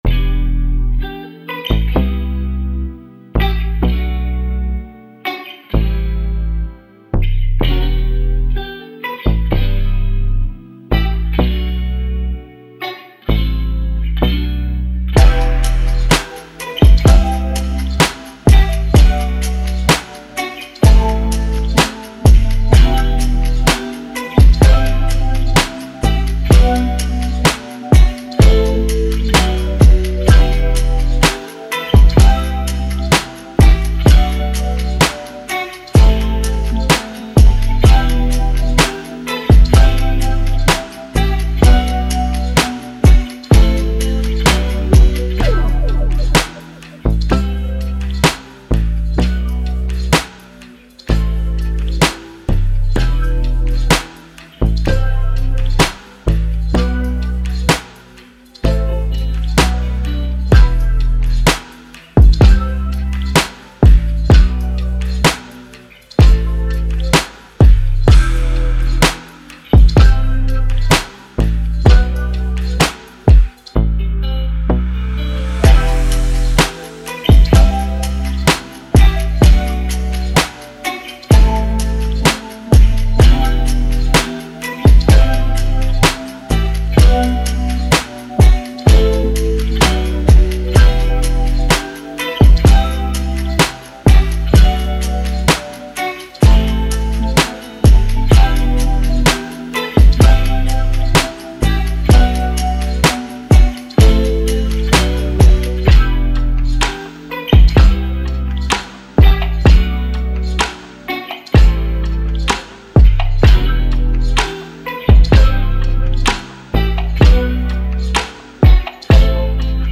R&B, Lofi
Em